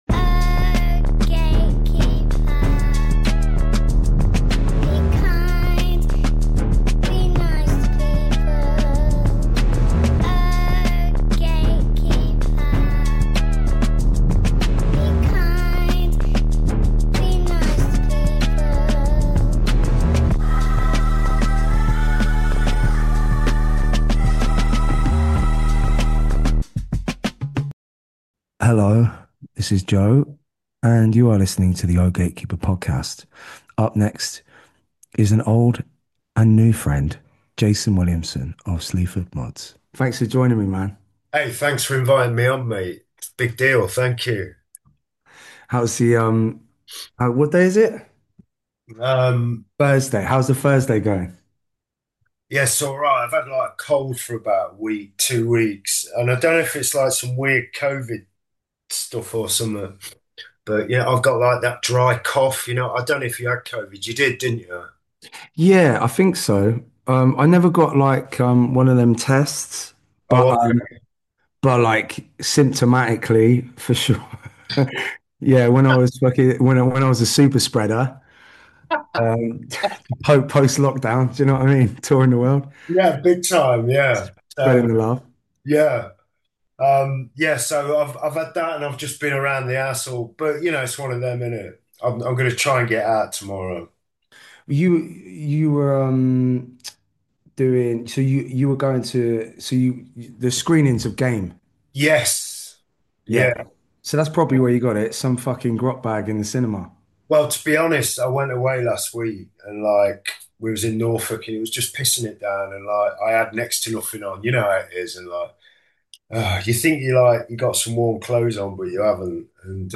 Each week Joe sits down for intimate one-to-one conversations with some of his favourite people in the world: be it artists, musicians or cultural icons, to explore their compulsion to create art. These raw and revealing discussions dig into process, purpose, and the personal truths that drive some of the most significant names in contemporary culture. This week we have Joe interview Jason Williamson from Sleaford Mods.